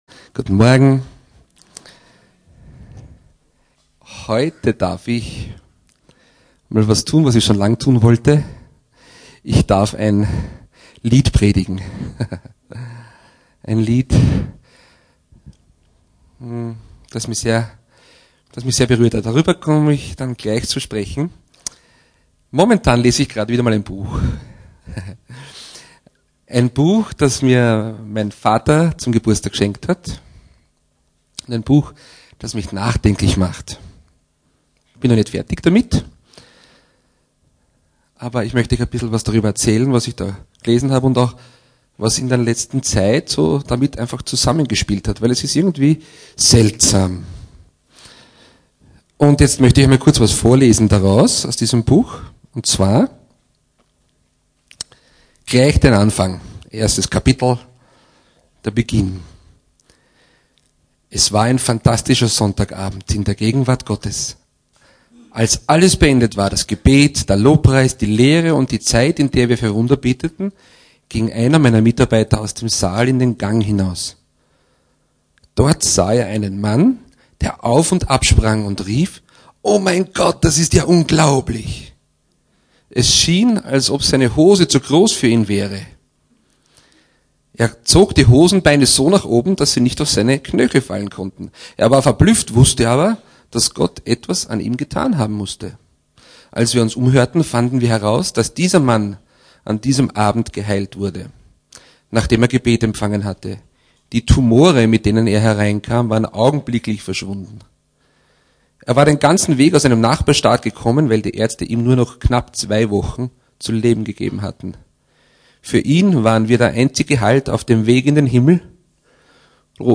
Hier finden Sie das Predigt Archiv für das Jahr 2011.